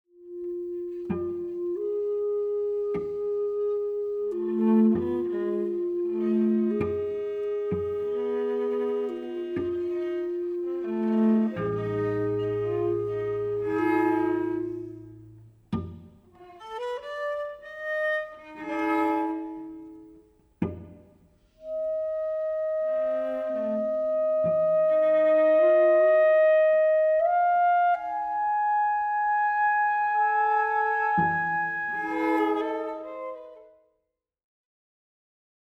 at bernard haitink hall of the conservatory of amsterdam
clarinet and shakuhachi
cello